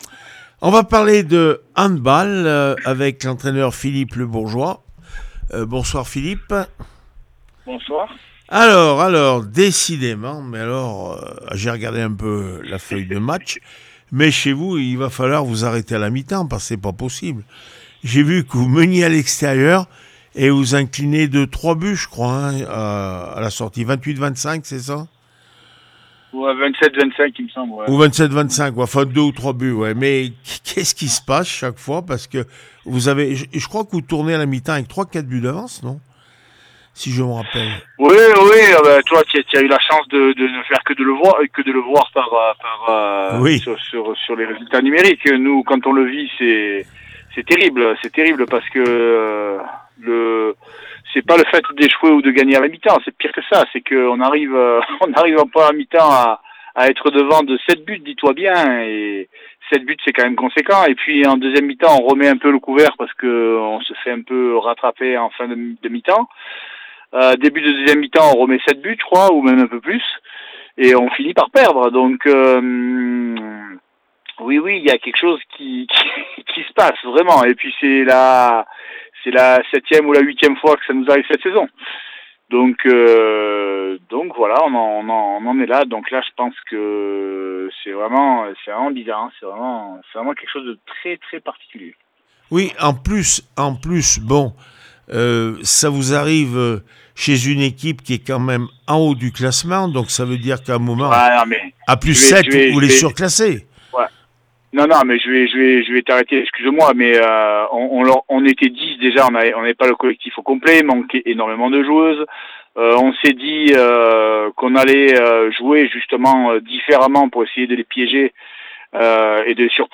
16 février 2026   1 - Sport, 1 - Vos interviews